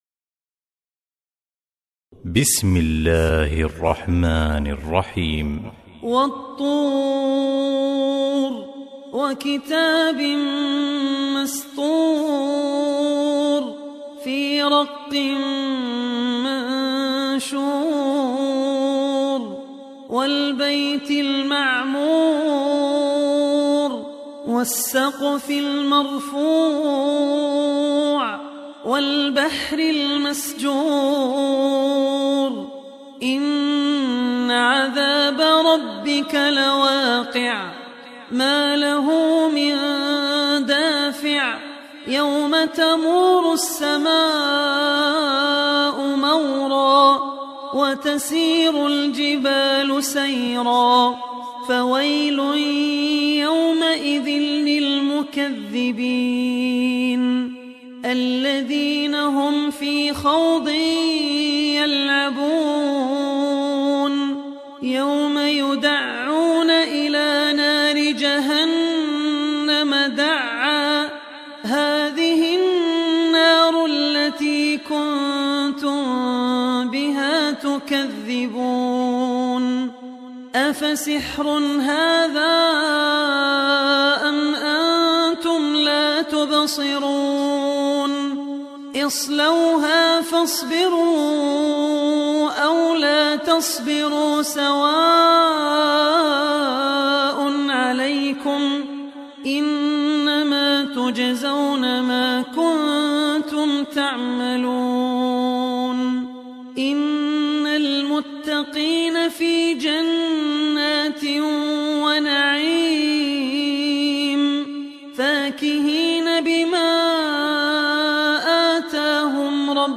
Surah At Tur Beautiful Recitation MP3 Download By Abdul Rahman Al Ossi in best audio quality.